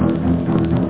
home *** CD-ROM | disk | FTP | other *** search / AMOS PD CD / amospdcd.iso / samples / jaws_2 ( .mp3 ) < prev next > Amiga 8-bit Sampled Voice | 1990-01-06 | 8KB | 1 channel | 8,363 sample rate | 1 second